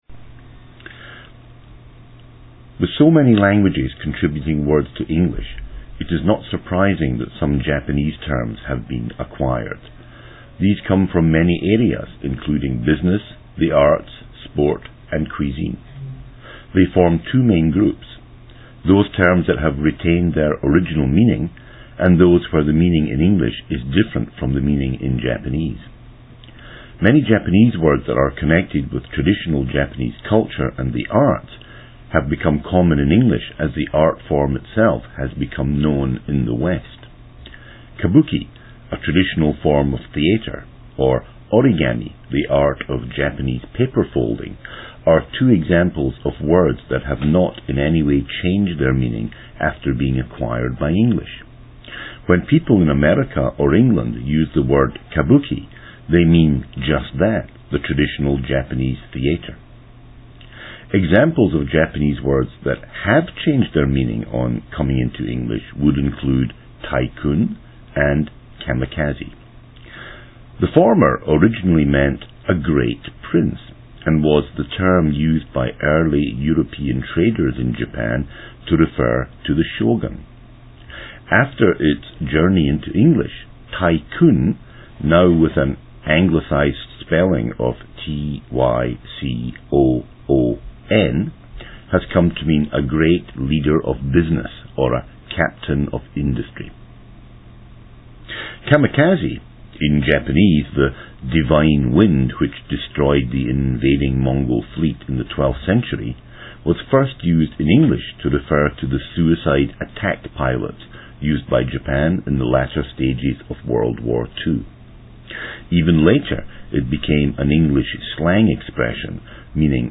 Listening passage